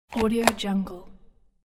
دانلود افکت صوتی مهر خوداستامپ
Automatic Stamp 3 royalty free audio track is a great option for any project that requires domestic sounds and other aspects such as a sfx, office and work.
Sample rate 16-Bit Stereo, 44.1 kHz
Looped No